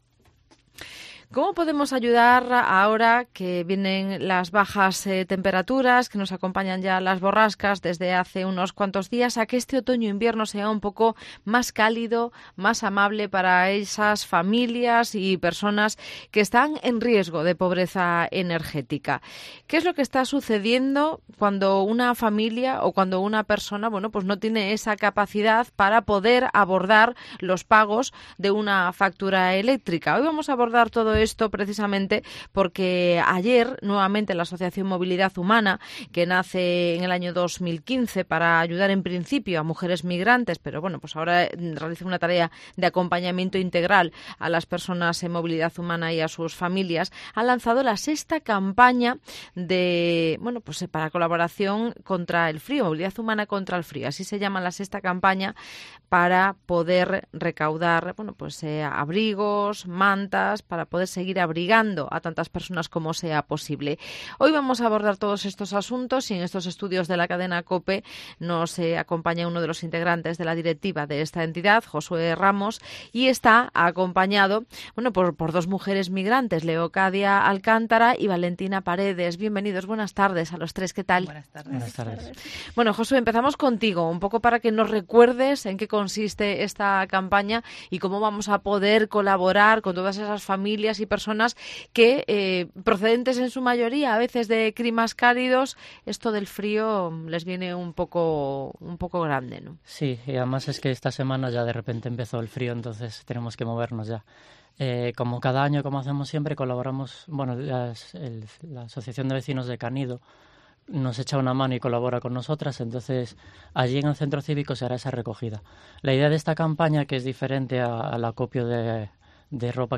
voluntarias e integrantes de Movilidad Humana